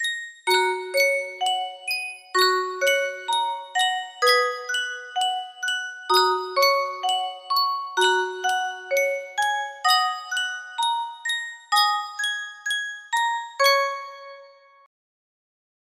Sankyo Music Box - Victory in Jesus CCQ
Full range 60